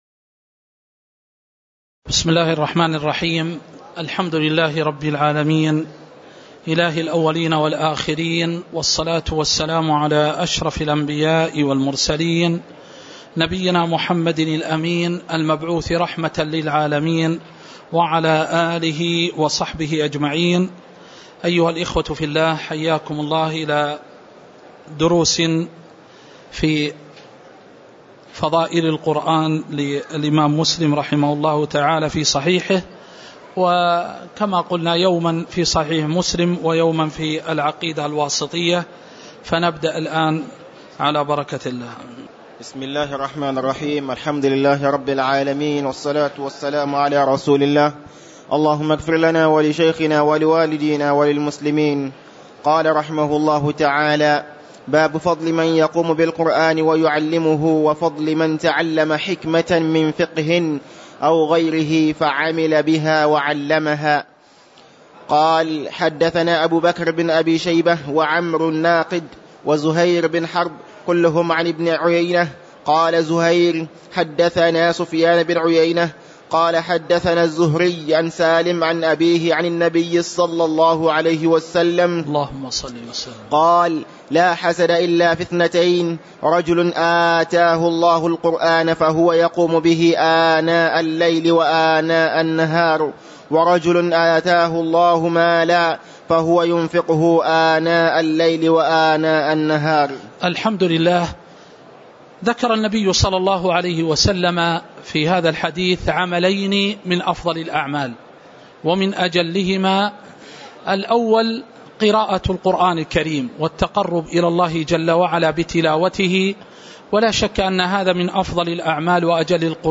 تاريخ النشر ٣ رجب ١٤٤١ هـ المكان: المسجد النبوي الشيخ